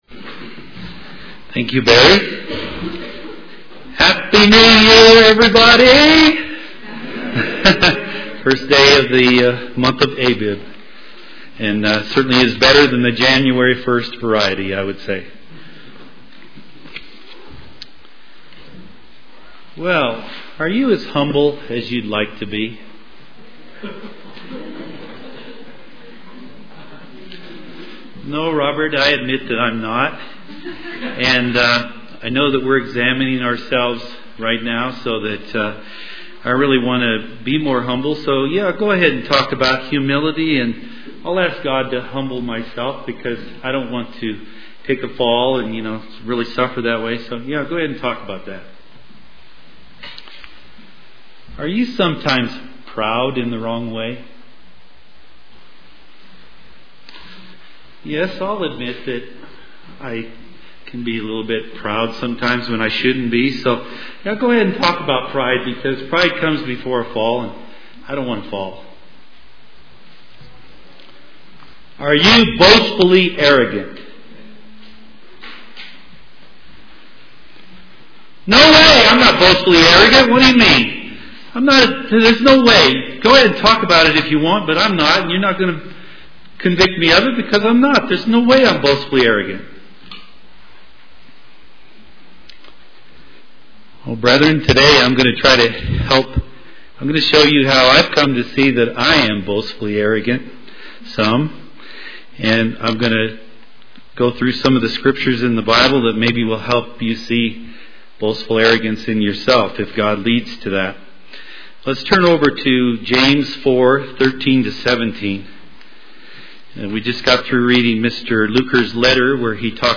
This split sermon considers the influence of Satan, society and self and aims to help us spot boastful arrogance in ourselves as we examine ourselves for Passover.
Given in Colorado Springs, CO